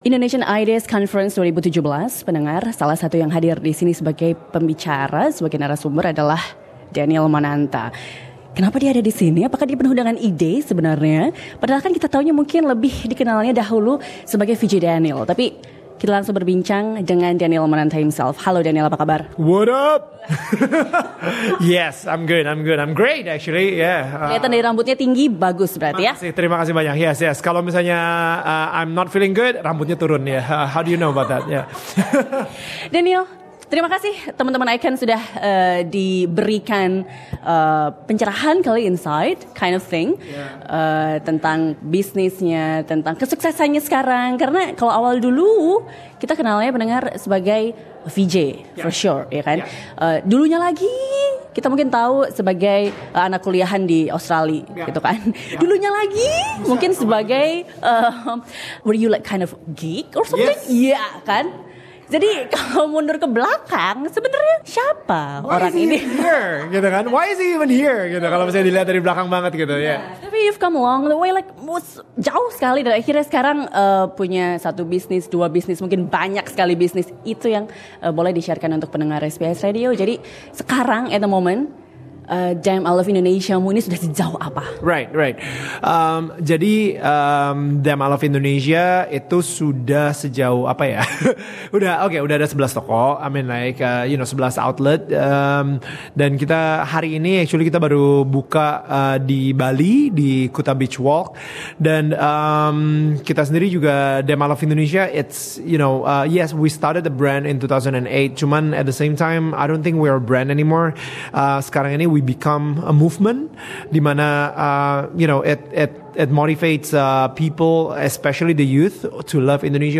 Interview with Daniel Mananta during ICON 2017. A well-claimed entertainer who once hosted Asian Idol which was screened in six countries, and won the Panasonic Global Awards 2013.